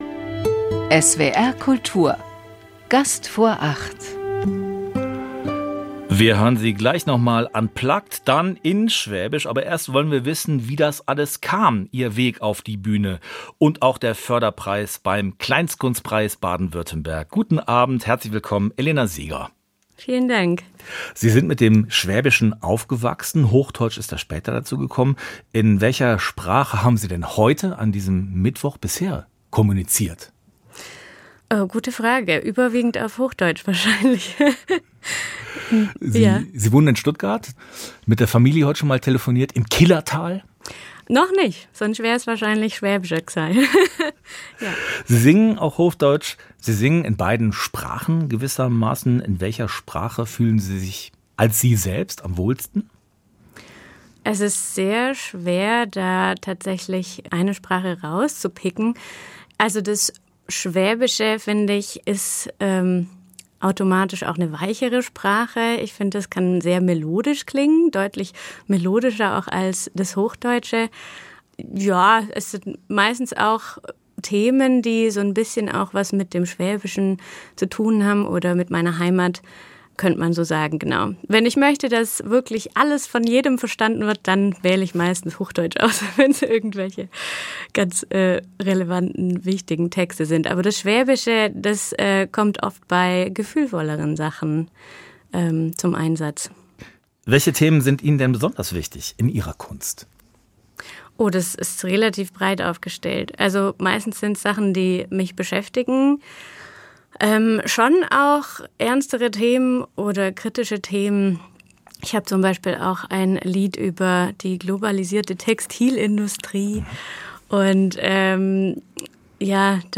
Zwischen Schwäbisch und Hochdeutsch